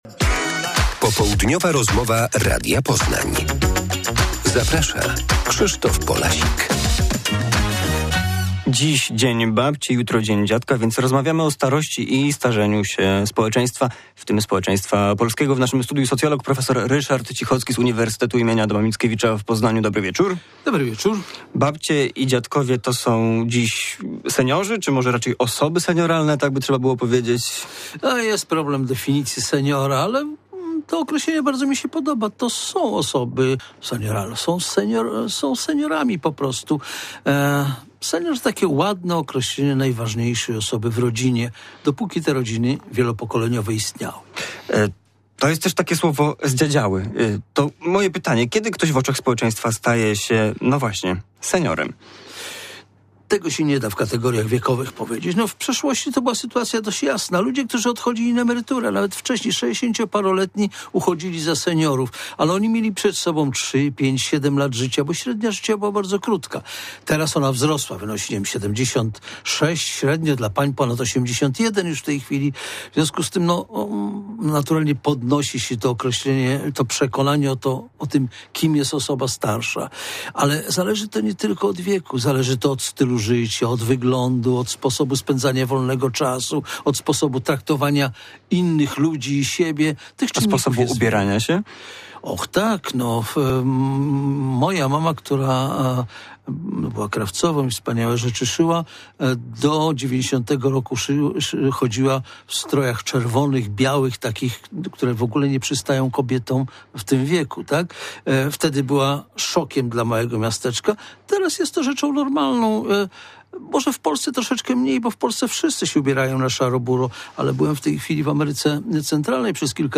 Popołudniowa rozmowa Radia Poznań